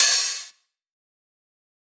Metro Nasty Open Hat.wav